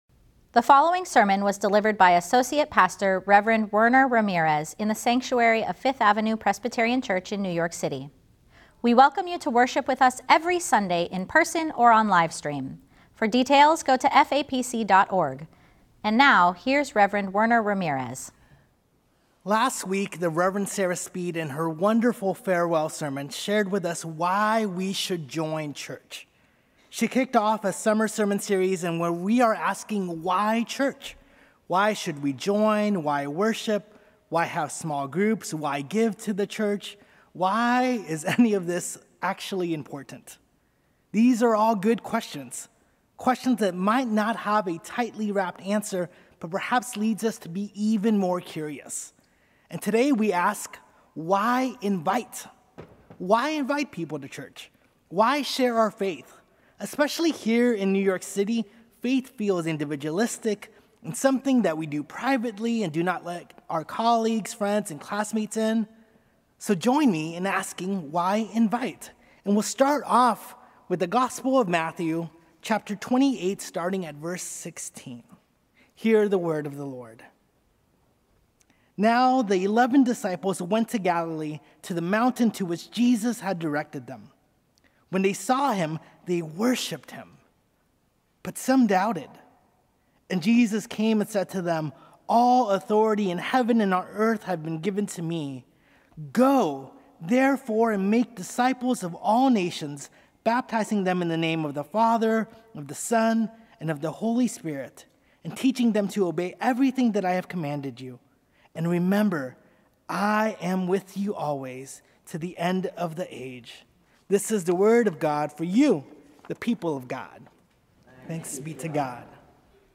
Tenth Sunday after Pentecost | Sermons at Fifth Avenue Presbyterian Church
Sermon: “Why Invite?”